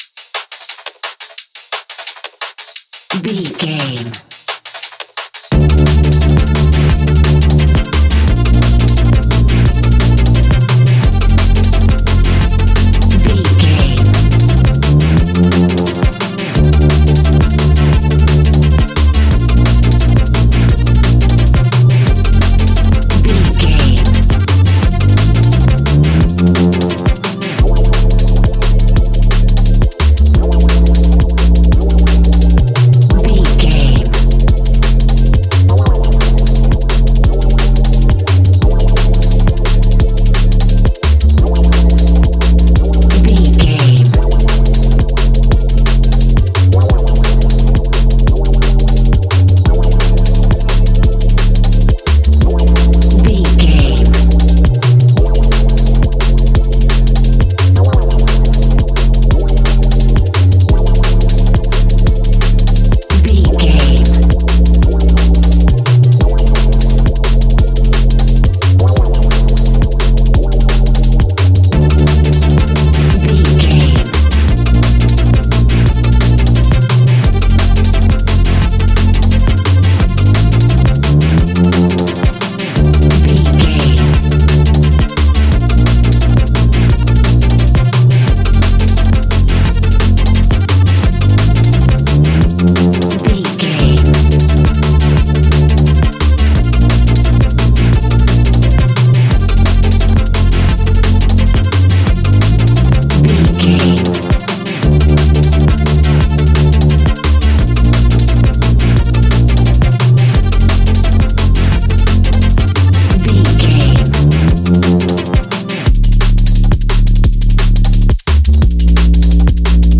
Ionian/Major
D♯
techno
trance
synths
synthwave
instrumentals